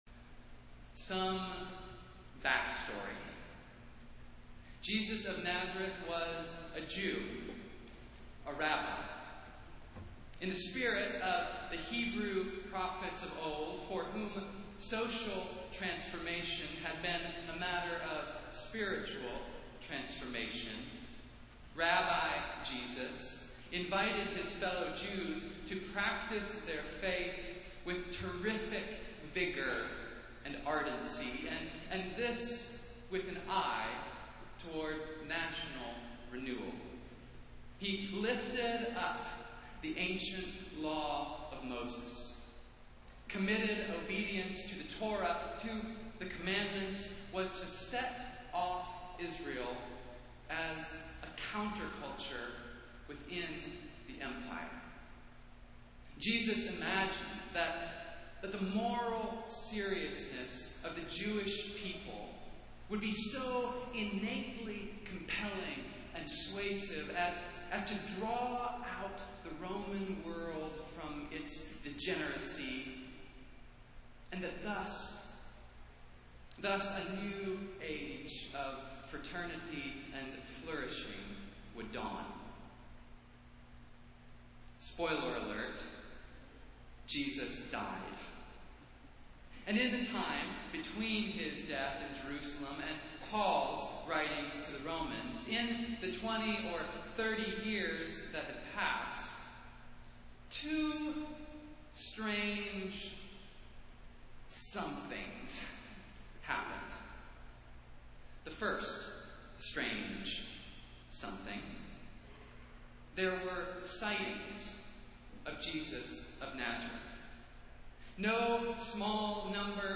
Festival Worship - Fifth Sunday after Pentecost